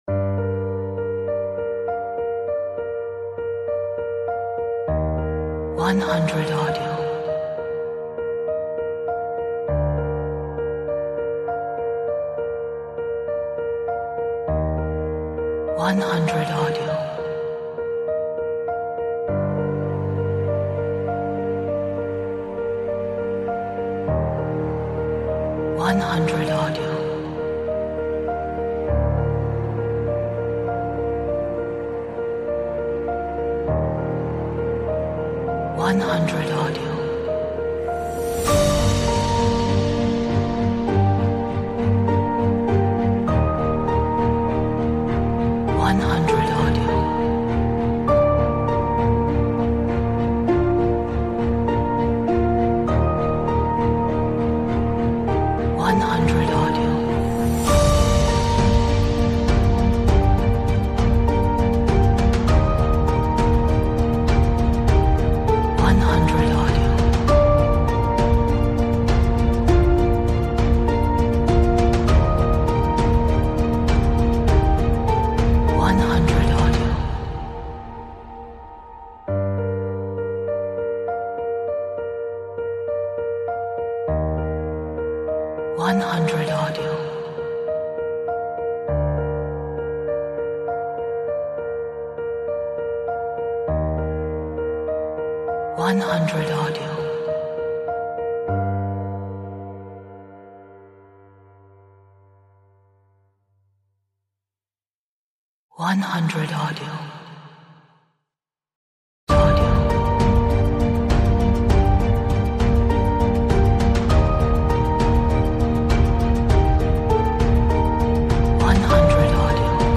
Atmospheric, dramatic, epic, energetic,